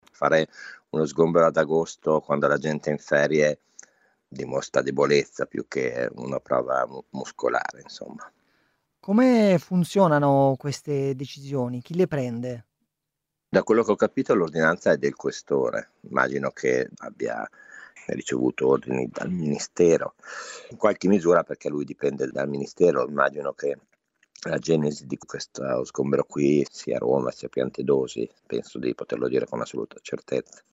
Lo abbiamo sentito durante lo sgombero: